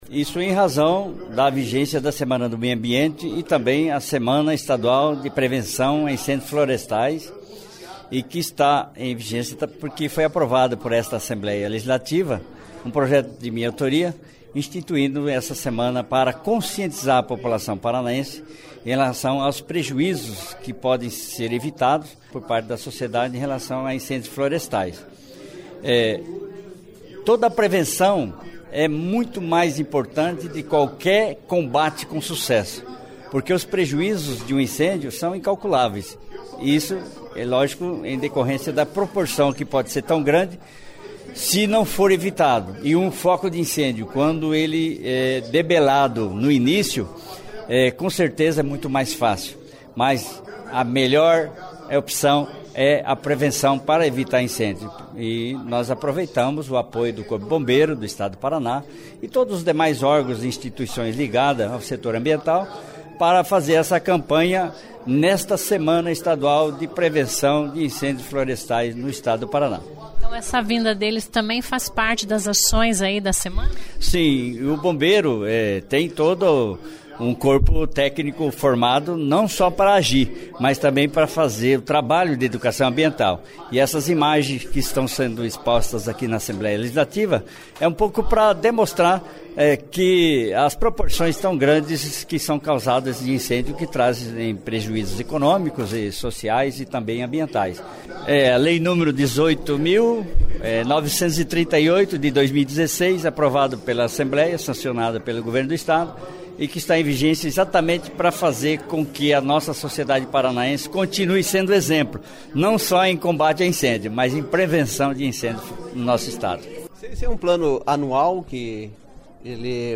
Ouça as entrevistas com o comandante do Corpo de Bombeiros do Paraná, coronel Fabio Mariano de Oliveira e do deputado Cláudio Palozzi (PSC), autor da lei estadual, que cria a Semana Estadual de Prevenção aos incêndios Florestais no Paraná. Eles falam principalmente da importância da prevenção antes de combater o fogo e das ações feitas ao longo da semana, celebrada este ano de 1º a 7 de junho.